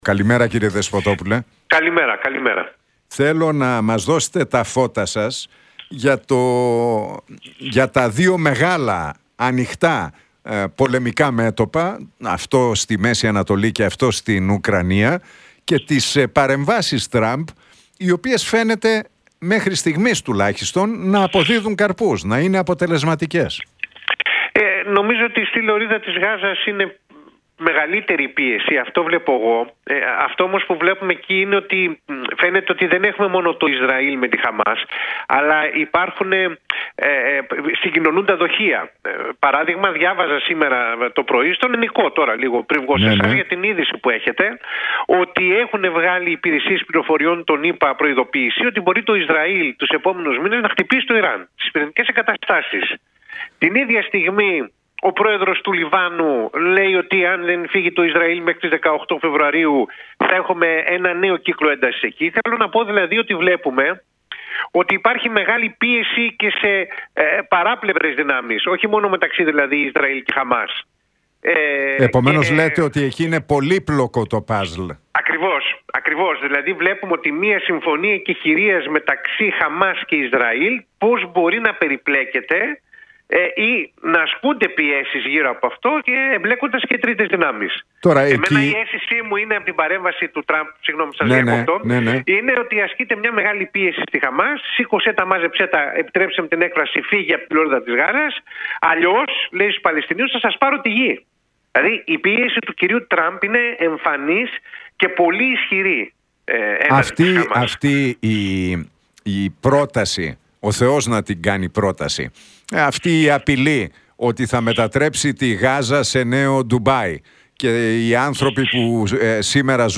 τη συμφωνία εκεχειρίας μεταξύ Ισραήλ και Χαμάς και τις τελευταίες κινήσεις του Ντόναλντ Τραμπ στον ρωσο-ουκρανικό πόλεμο και τις ελληνοτουρκικές σχέσεις μίλησε ο διεθνολόγος
από την συχνότητα του Realfm 97,8.